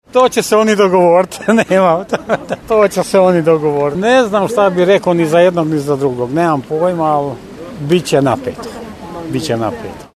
Anketa
Za koga glasa gospođa s kojom smo porazgovarali na Gradskoj tržnici